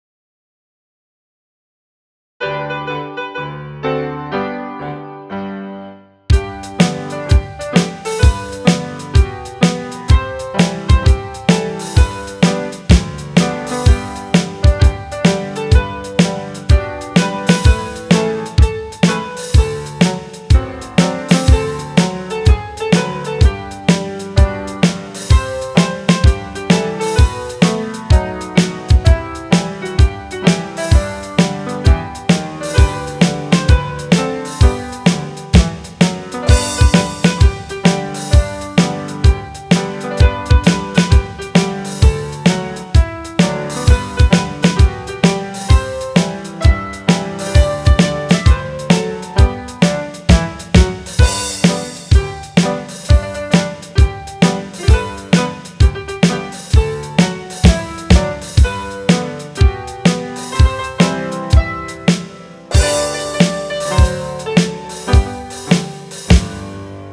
Midi File, Lyrics and Information to I've Been Working on the Railroad